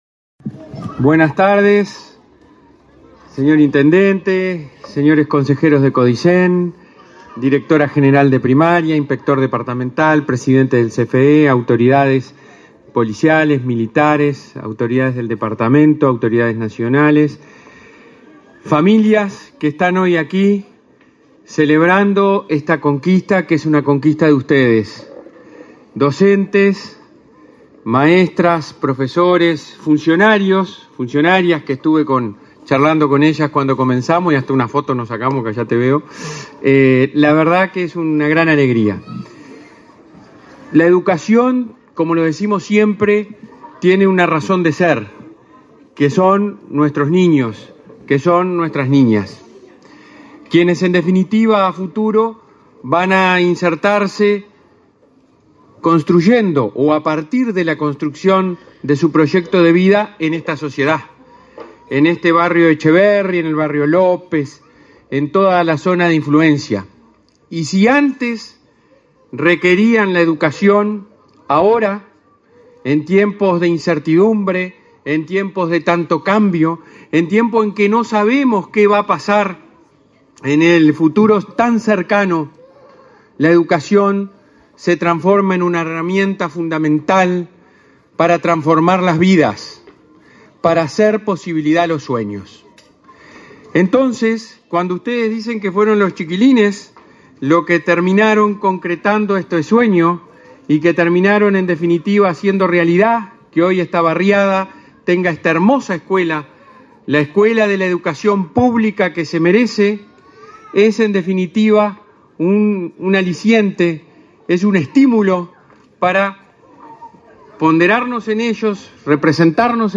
Conferencia de prensa por la inauguración de la escuela n.° 124 en Tacuarembó